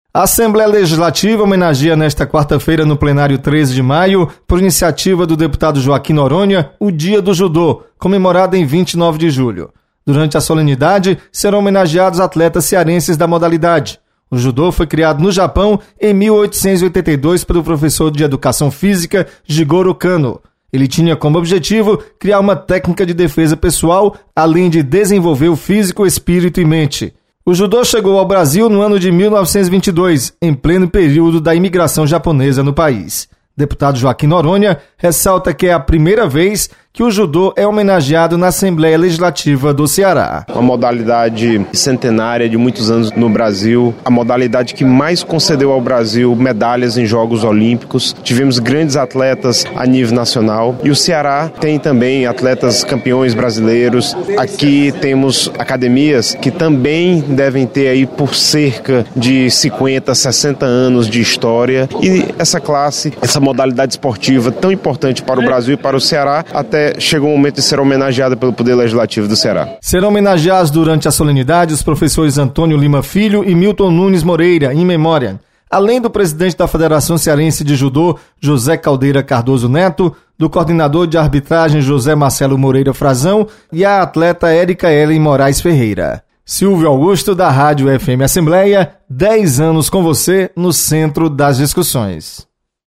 Assembleia presta homenagem ao Dia do Judô. Repórter